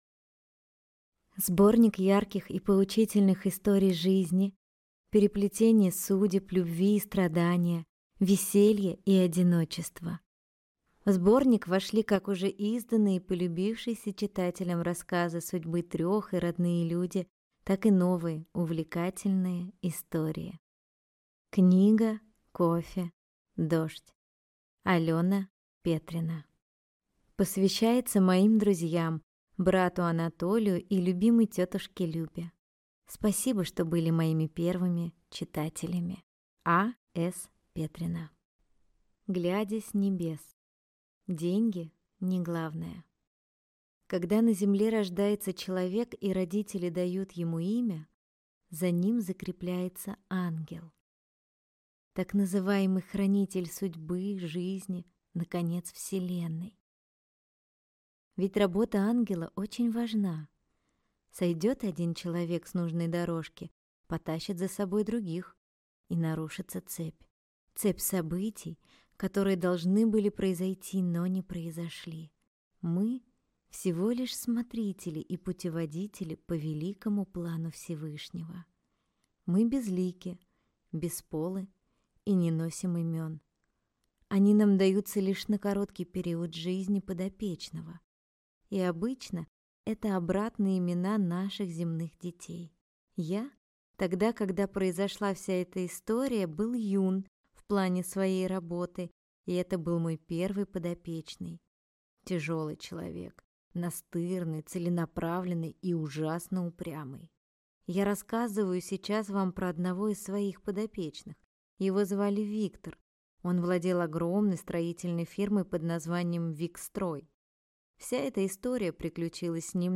Аудиокнига Книга. Кофе. Дождь. Сборник рассказов | Библиотека аудиокниг